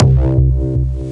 Distorted Wobble.wav